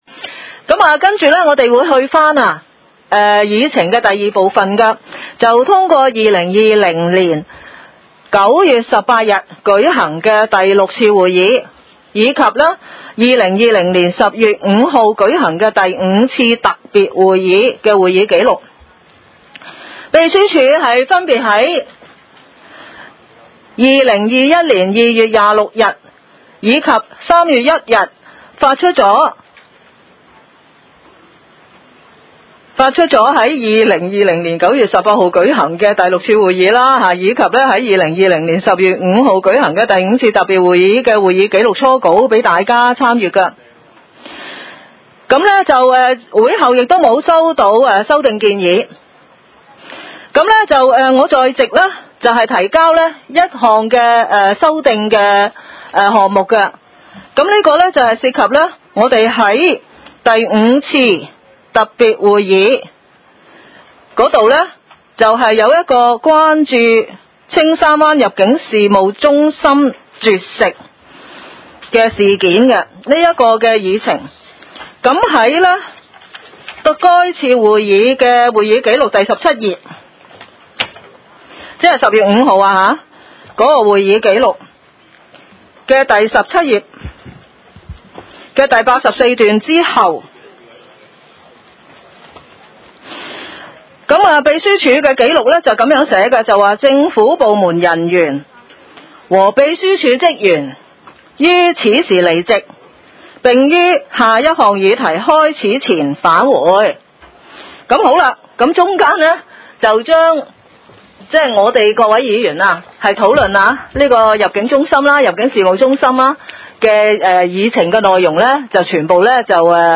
屯門區議會會議室